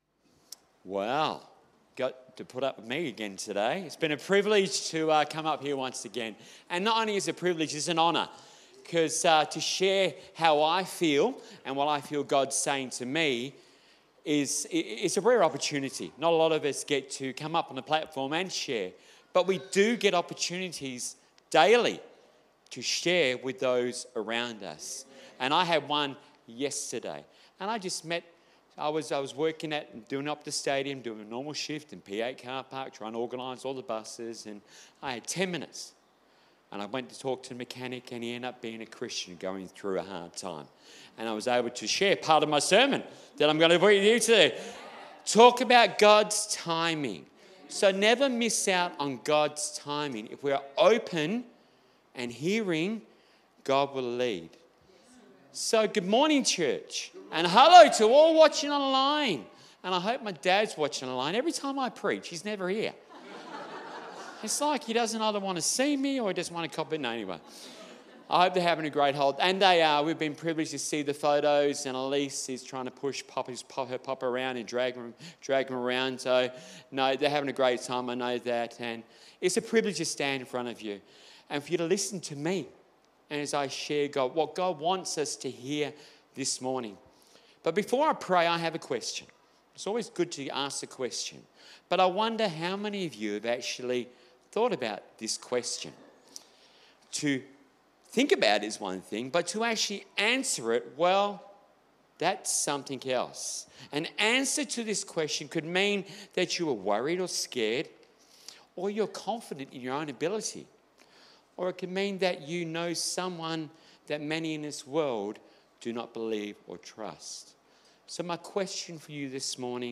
Sermon Transcript Is your future going to be good?